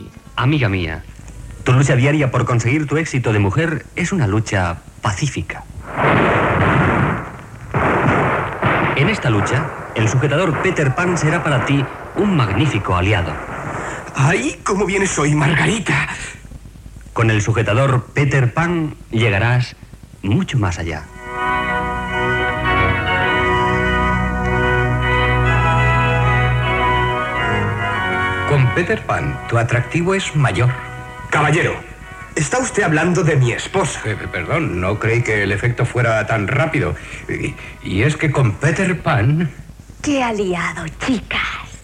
Extret de Crònica Sentimental de Ràdio Barcelona emesa el dia 22 d'octubre de 1994.